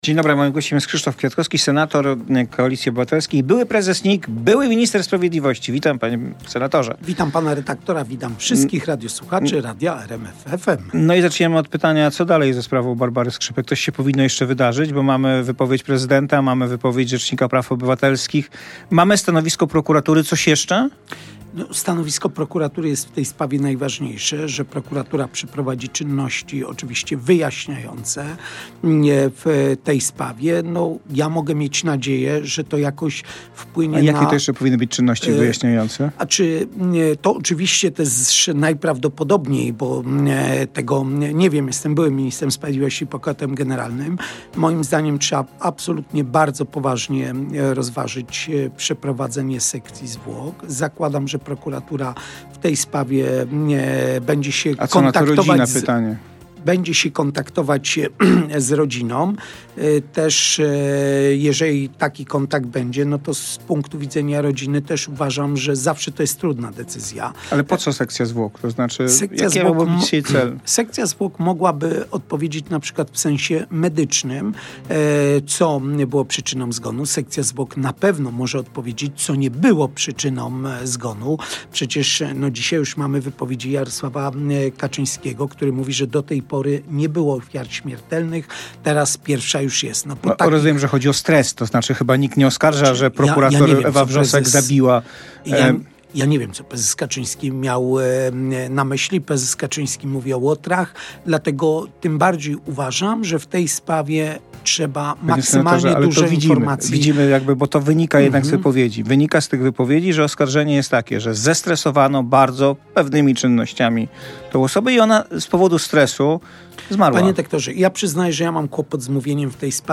Na poranną publicystykę zaprasza Tomasz Terlikowski.
Gośćmi są nie tylko politycy, ale i ludzie ze świata kultury czy sportu.